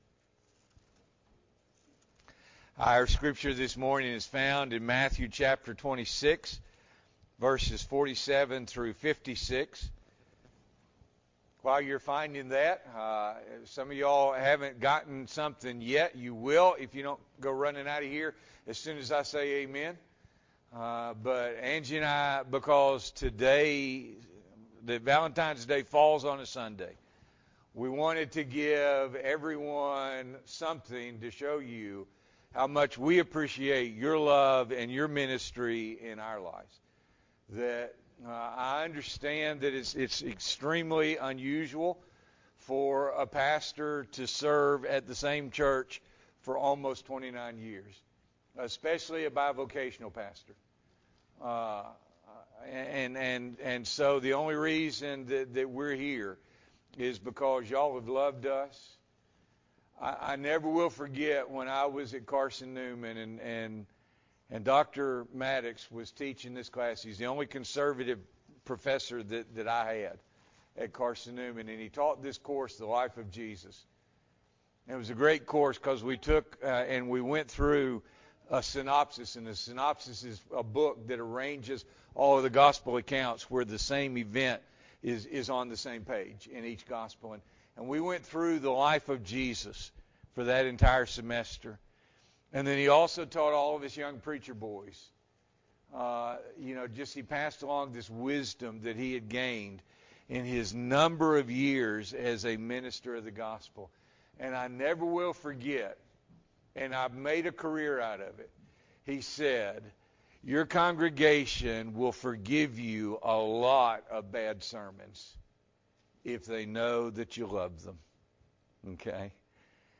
February 14, 2021 – Morning Worship